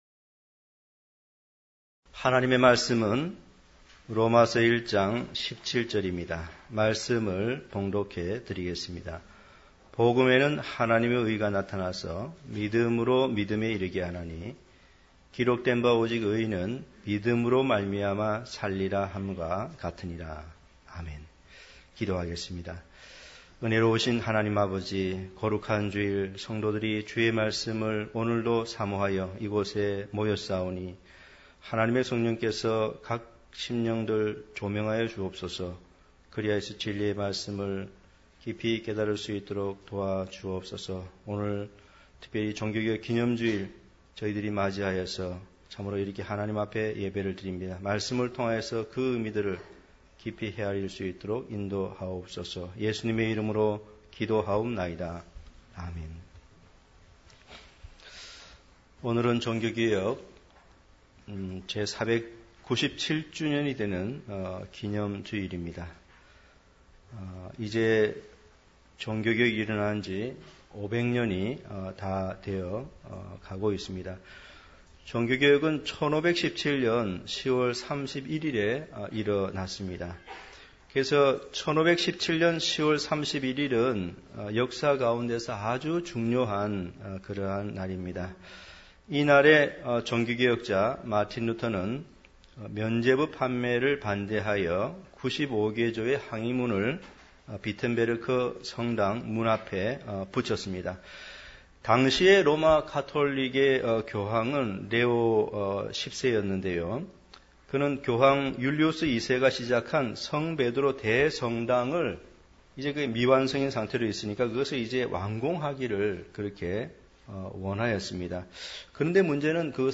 종교개혁의 역사와 의미 > 단편설교 | 진리교회